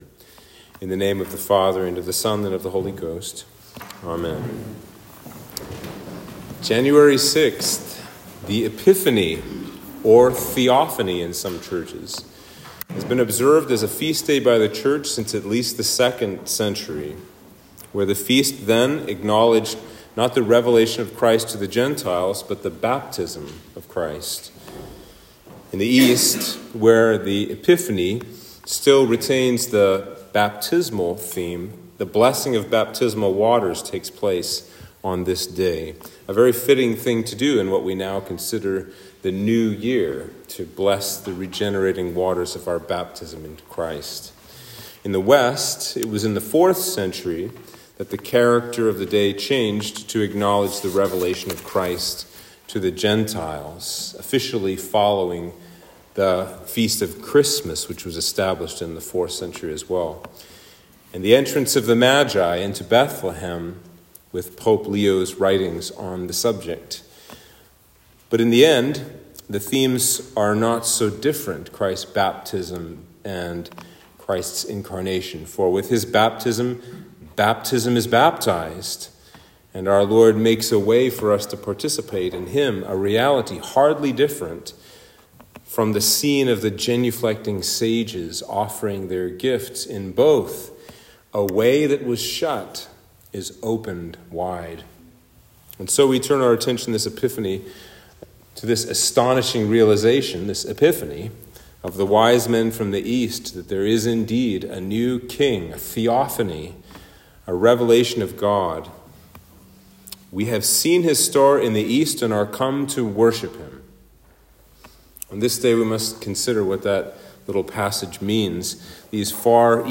Sermon for Epiphany